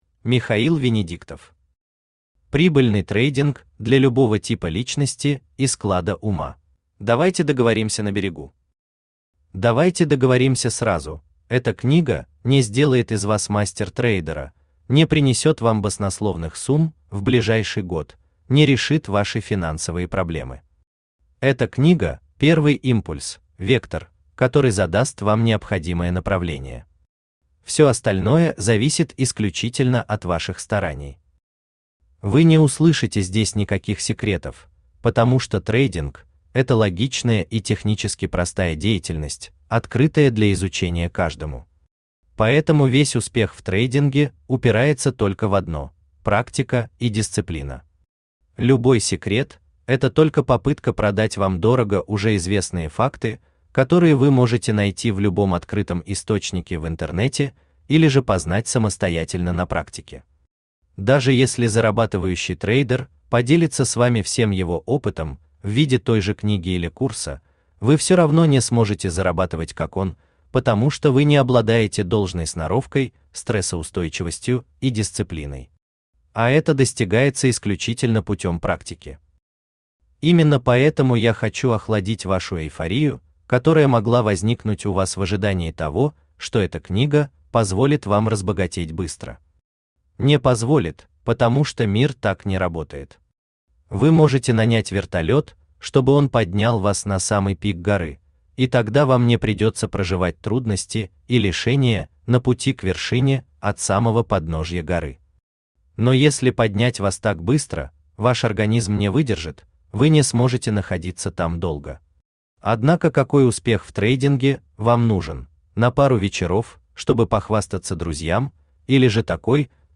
Аудиокнига Прибыльный трейдинг для любого типа личности и склада ума | Библиотека аудиокниг
Aудиокнига Прибыльный трейдинг для любого типа личности и склада ума Автор Михаил Венедиктов Читает аудиокнигу Авточтец ЛитРес.